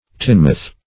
tinmouth - definition of tinmouth - synonyms, pronunciation, spelling from Free Dictionary Search Result for " tinmouth" : The Collaborative International Dictionary of English v.0.48: Tinmouth \Tin"mouth`\, n. (Zool.)